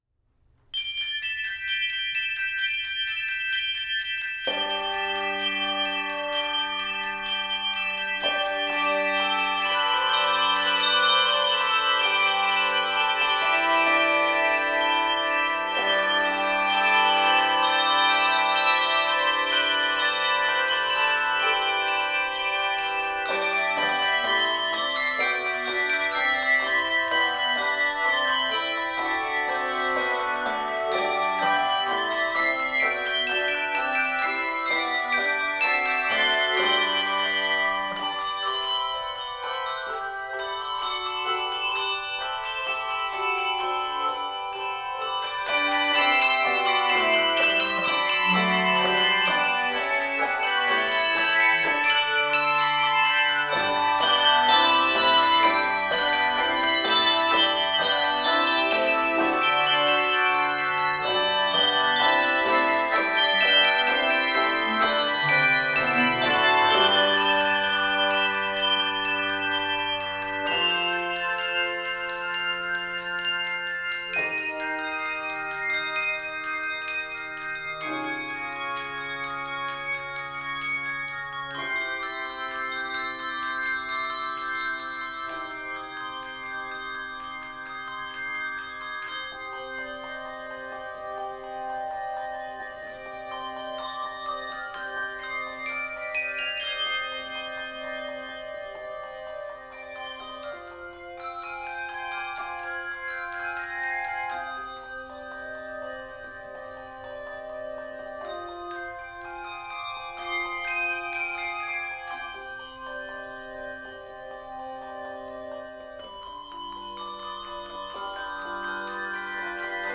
Keys of G Major and c minor.